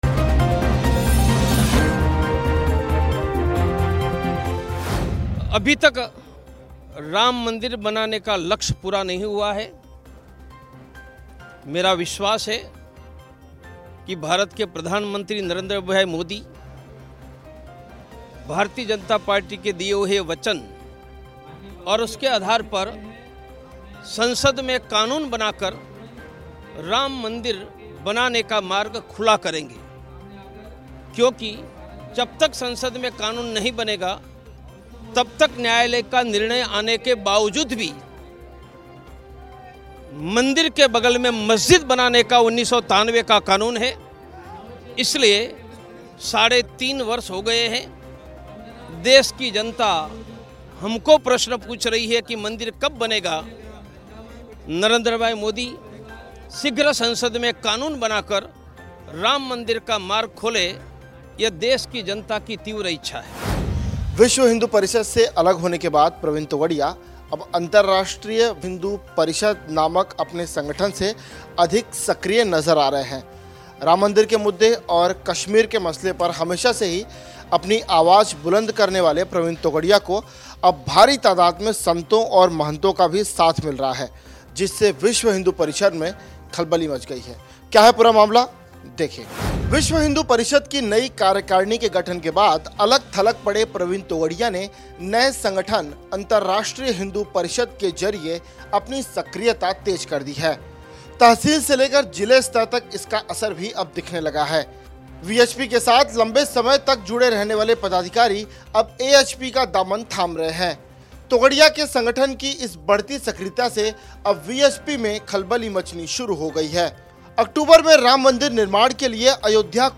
न्यूज़ रिपोर्ट - News Report Hindi / प्रवीण तोगड़िया का बड़ा ऐलान, लोकसभा चुनाव में बढ़ाएंगे बीजेपी का टेंशन